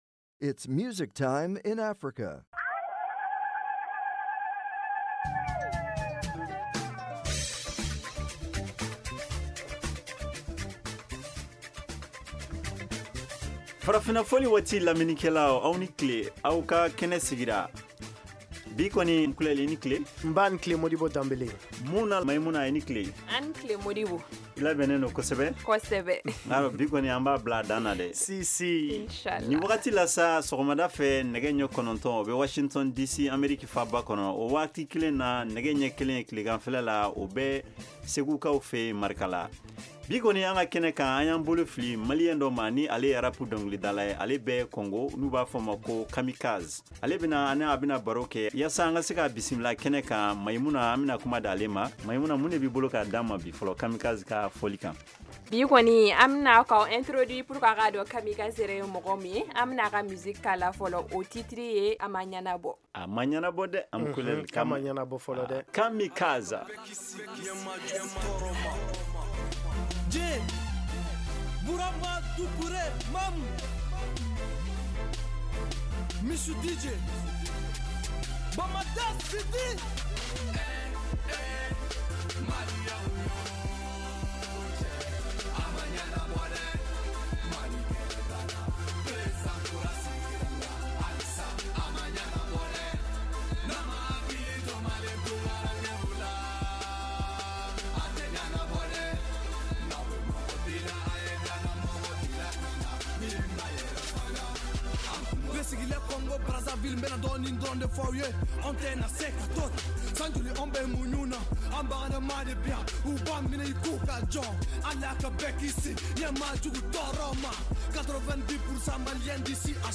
Farafina Fɔli Waati est une émission culturelle et musicale interactive en Bambara de la VOA.